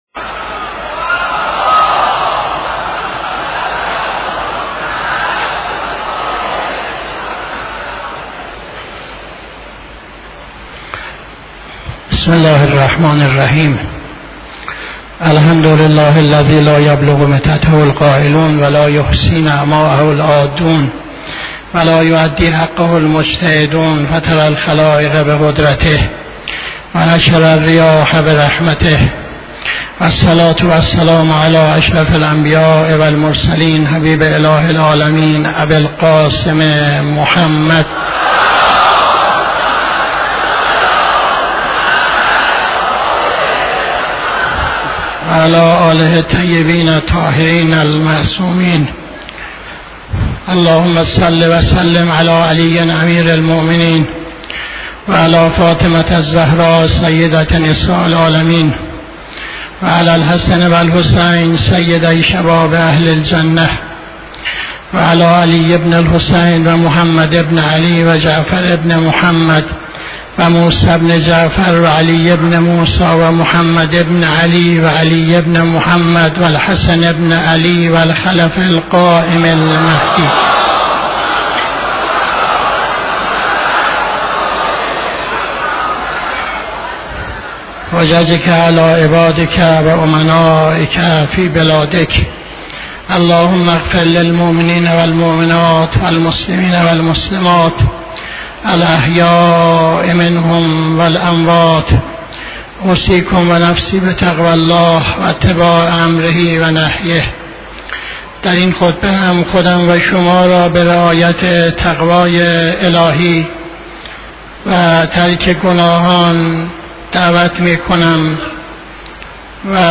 خطبه دوم نماز جمعه 09-06-80